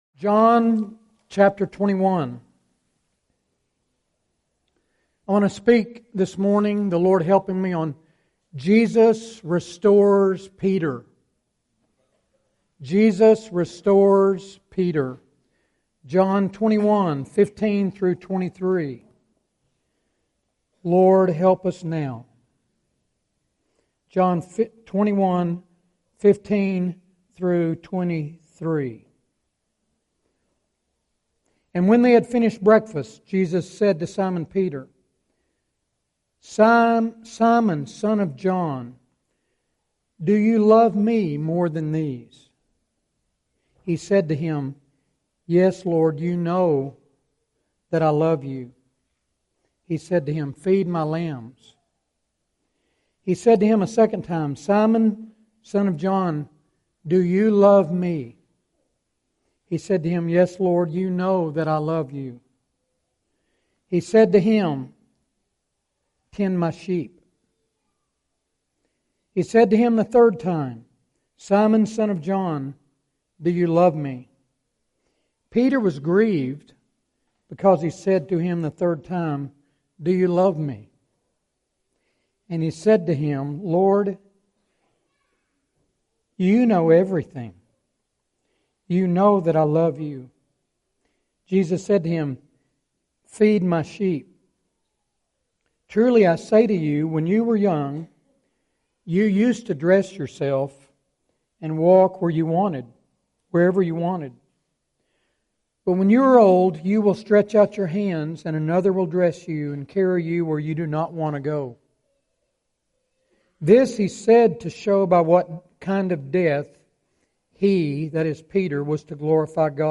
2016 Category: Full Sermons